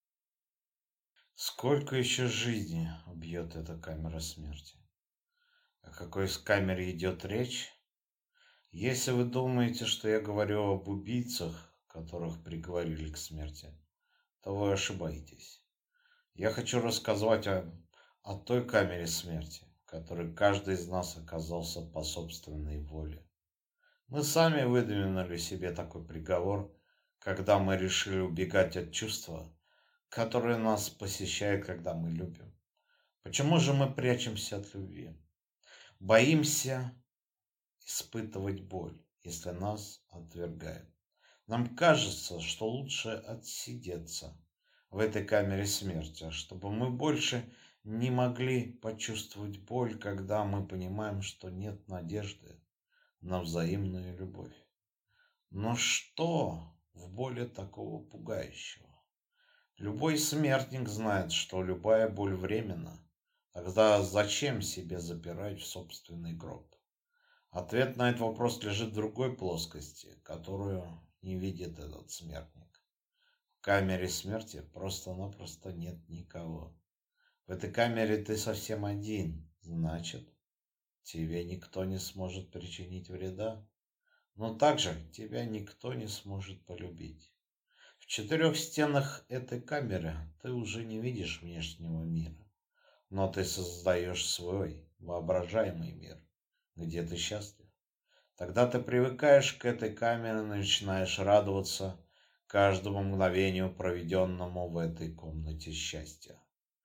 Аудиокнига Камера смерти | Библиотека аудиокниг